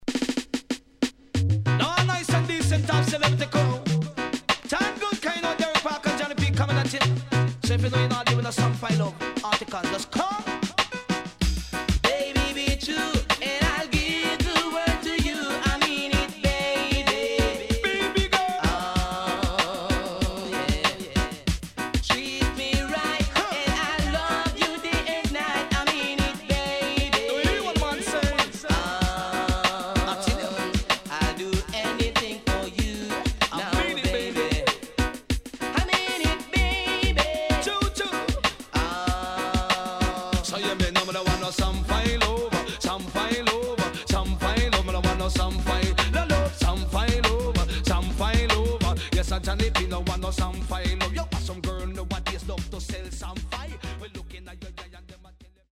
HOME > Back Order [DANCEHALL LP]